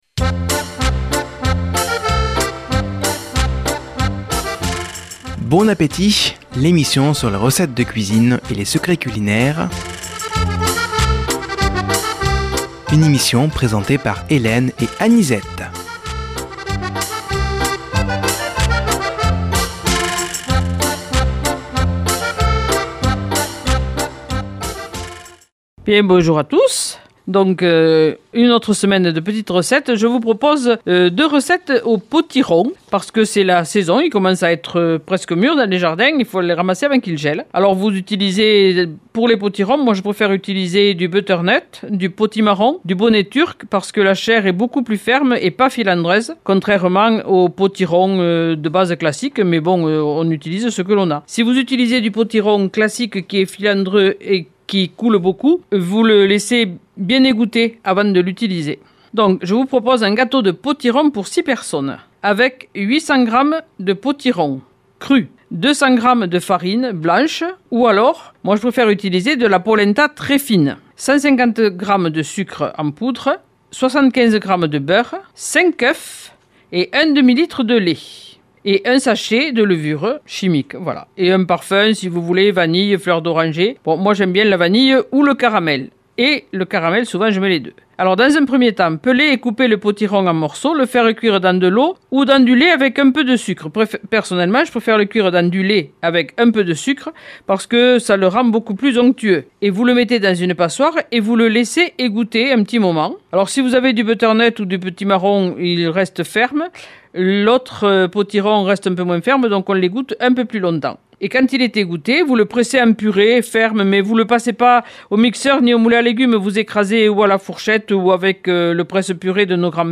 Nouvelle émission de cuisine avec aujourd'hui notamment des Recettes au Potiron